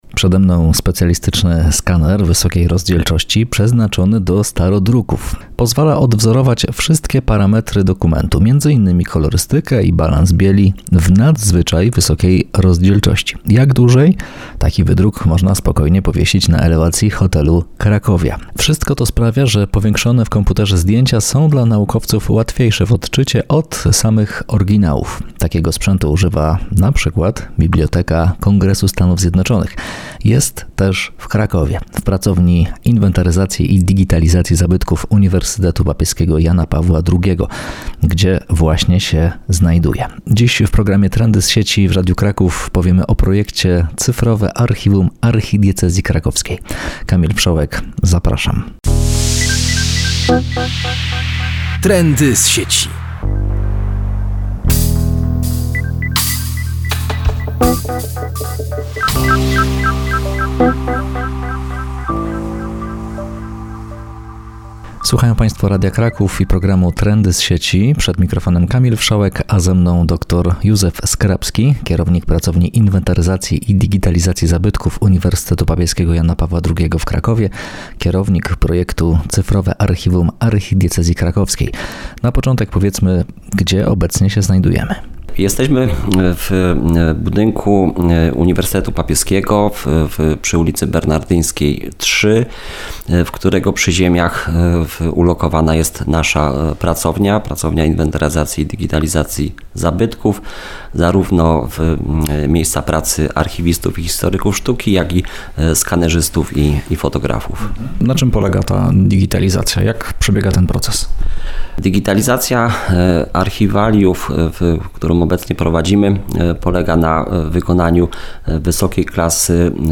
Wywiad odbył się w ramach audycji "Trendy z sieci", która analizuje najpopularniejsze tematy ze świata nauki i nowych technologii.